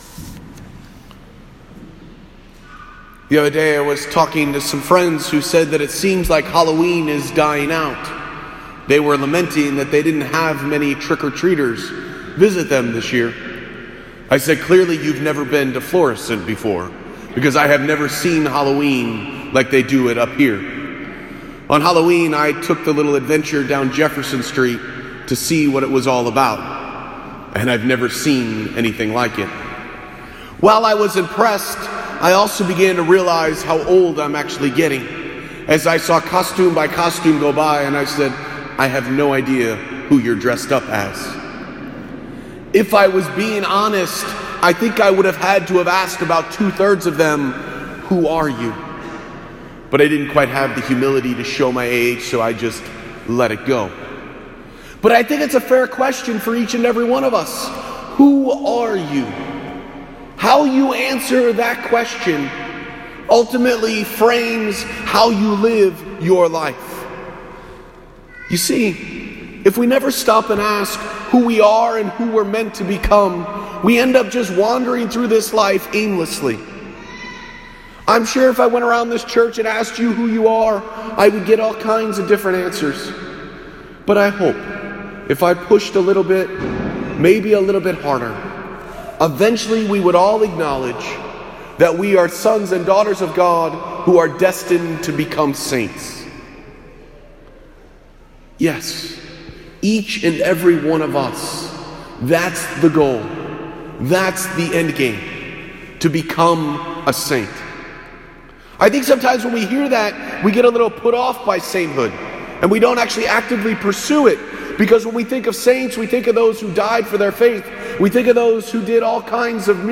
Posted in Homily